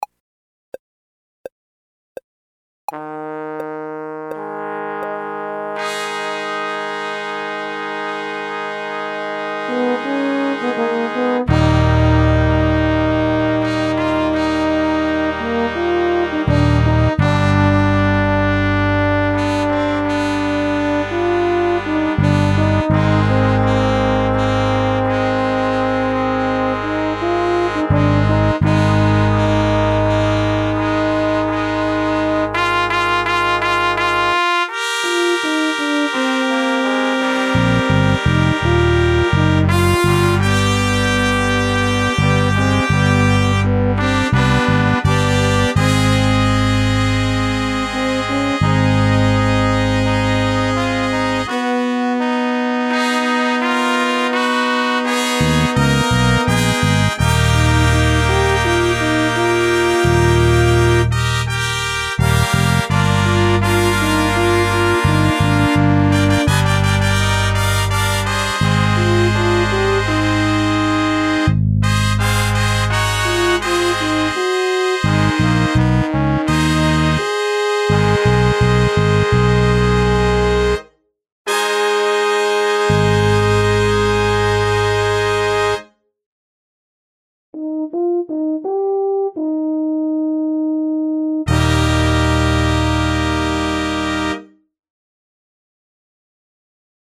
Trp 1 Trp 2 Pos 1 Pos 2 Horn BDrum Lyr Sax